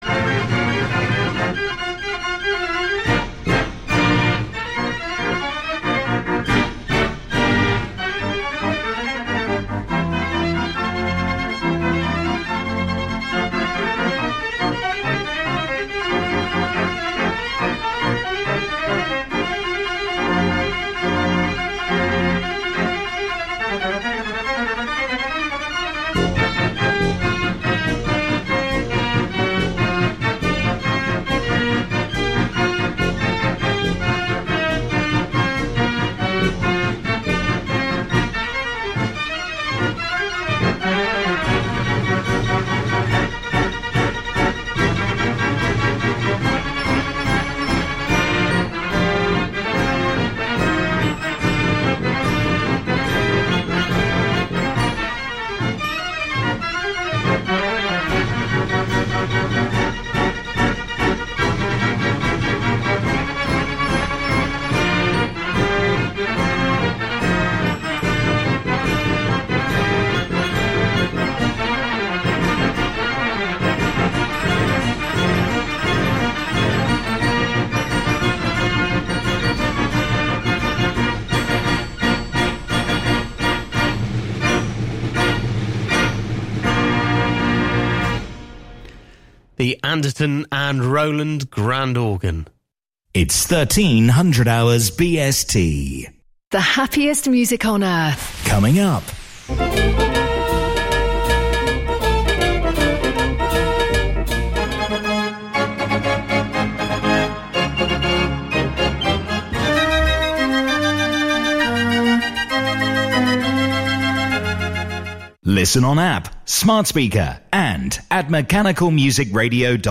You're never far away from a style of mechanical music you love.